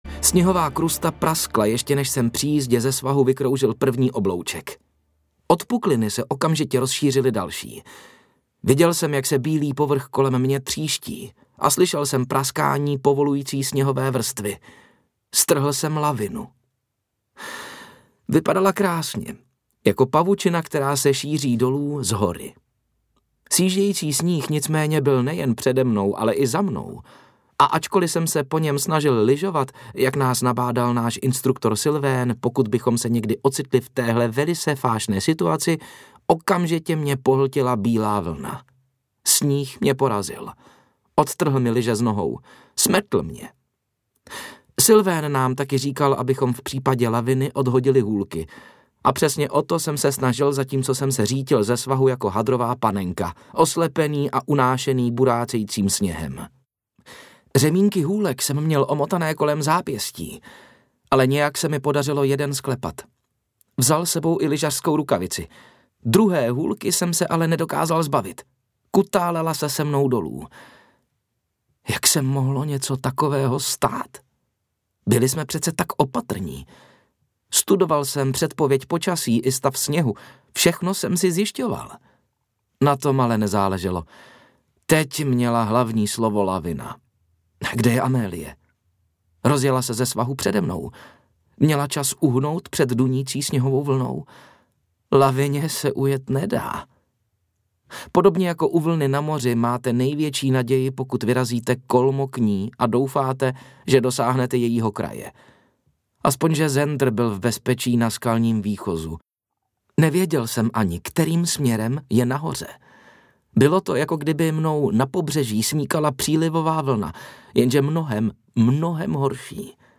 Interpret:
Obálka audioknihy Průtrž